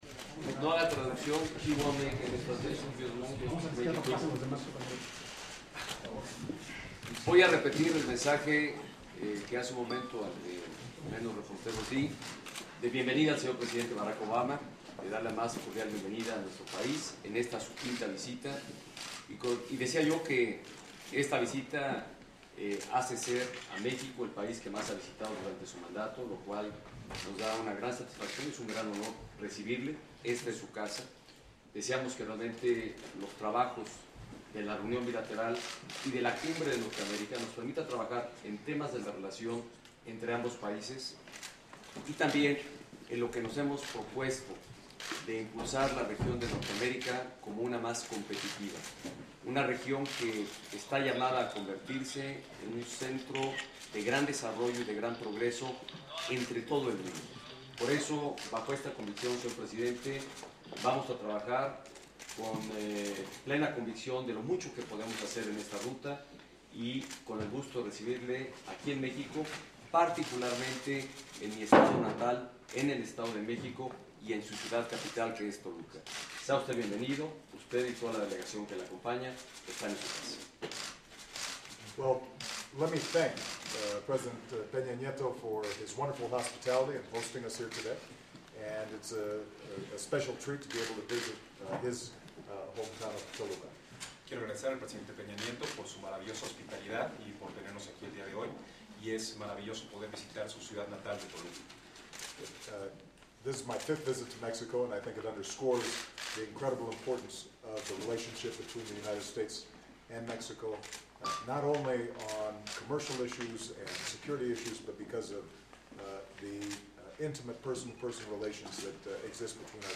President Obama speaks to the press before a bilateral meeting with President Peña Nieto of Mexico.